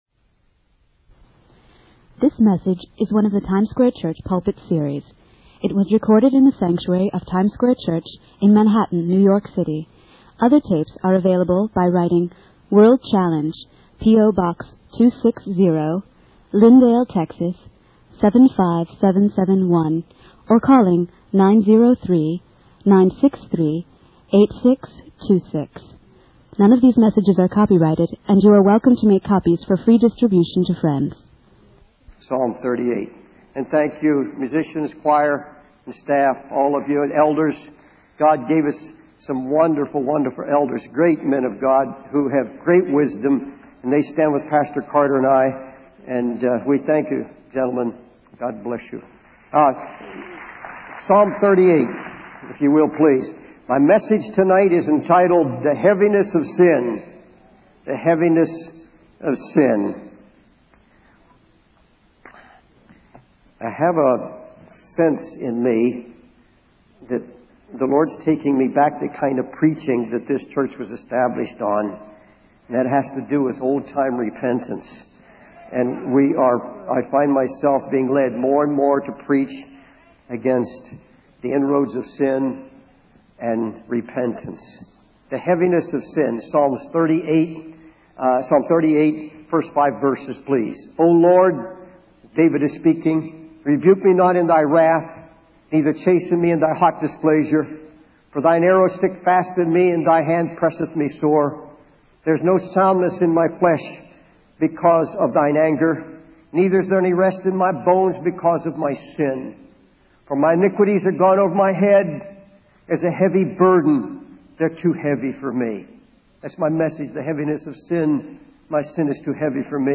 In this sermon, the preacher emphasizes the importance of being convicted by the Holy Spirit and allowing God to peel away the layers of sin in one's life.